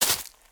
footsteps
decorative-grass-10.ogg